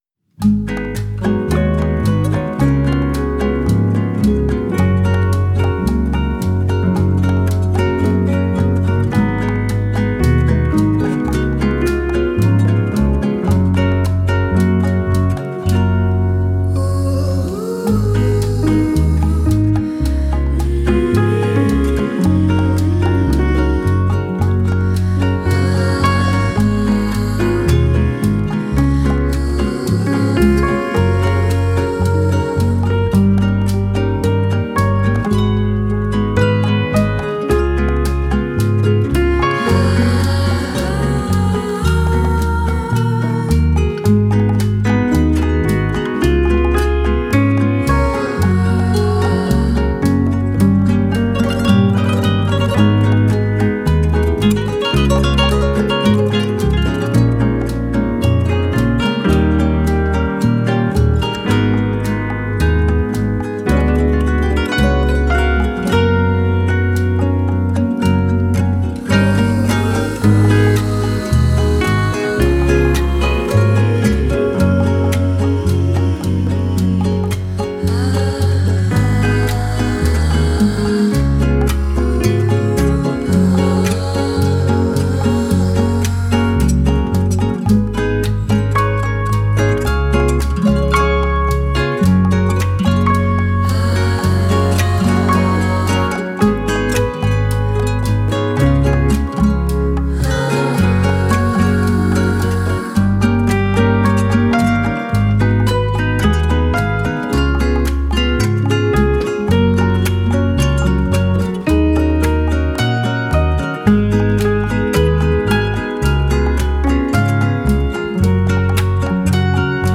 Home > Music > Romantic